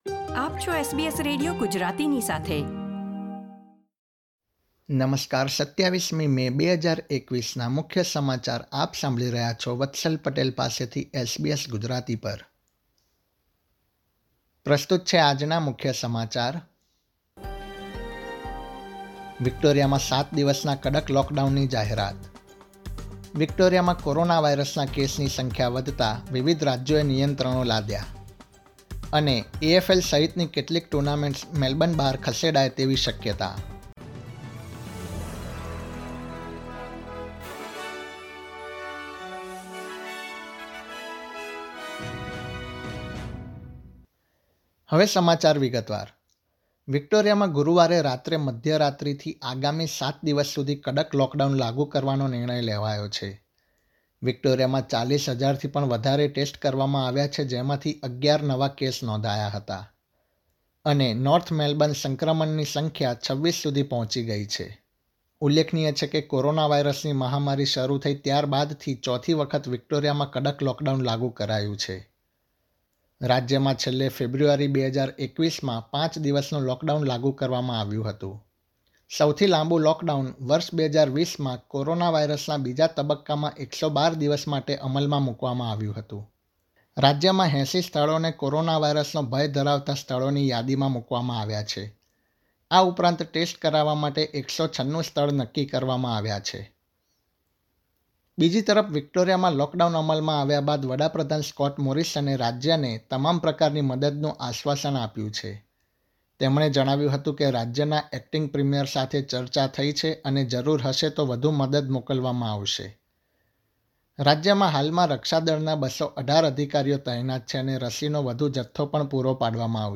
SBS Gujarati News Bulletin 27 May 2021
gujarati_2705_newsbulletin.mp3